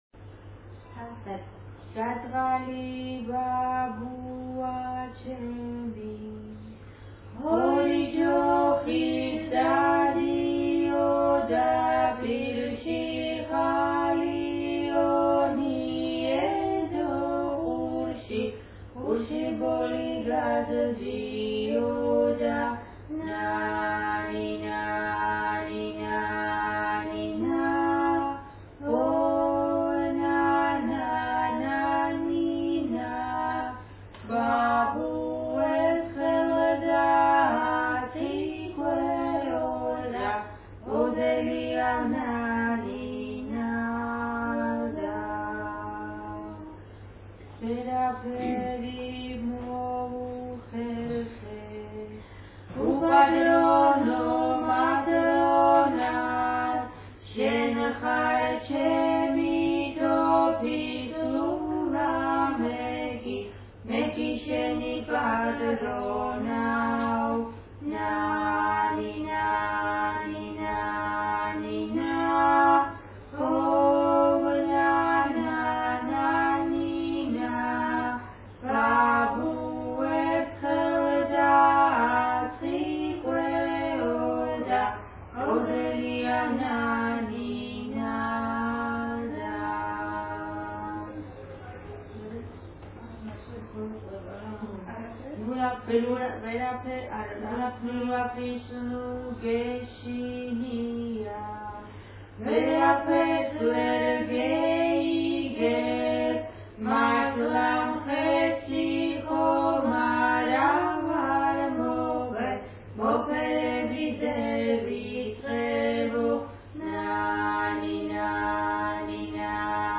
The second is a folksong,
here, the two women sing it alone.